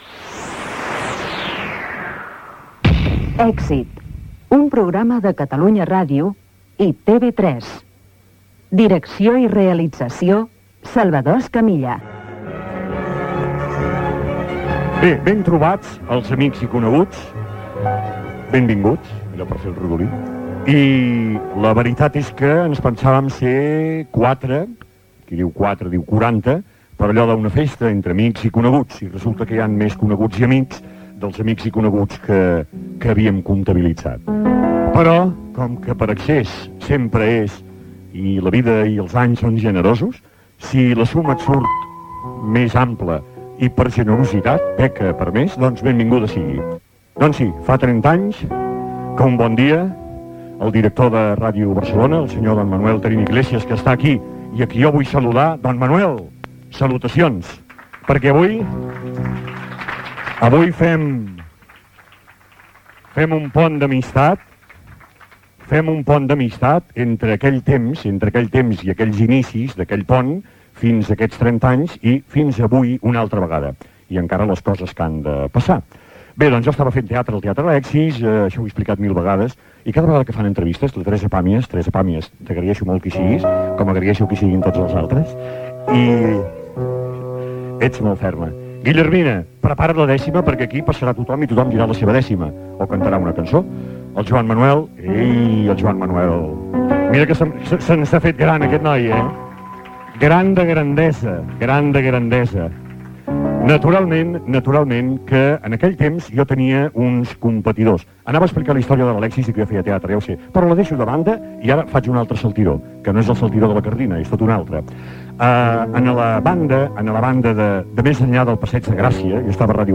Presentació del programa amb els agraïments als assistents. Fet des del Restaurant Via Fora amb motiu del 30è aniversari del programa Radio-Scope de Ràdio Barcelona.
Entreteniment